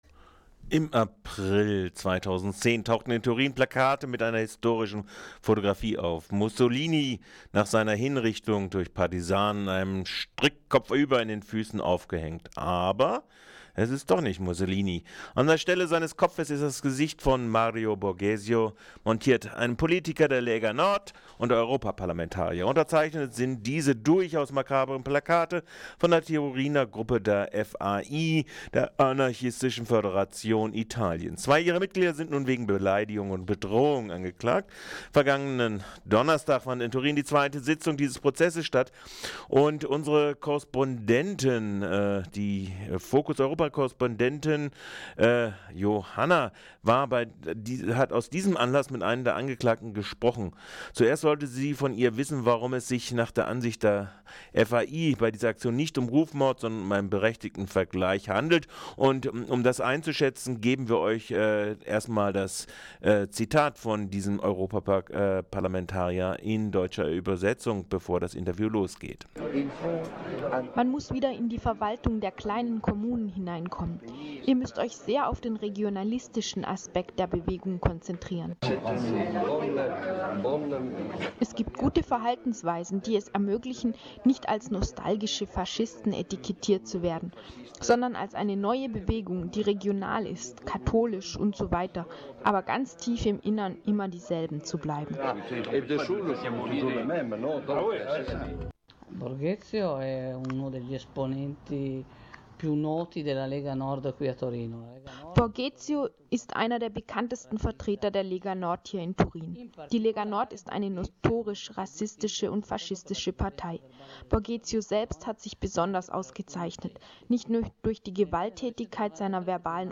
Interview mit einer Aktivistin der Anarchistischen Föderation Italiens über den Rassismus des EP-Abgeordneten und Lega-Nord-Politikers Borghezio und Gegenaktionen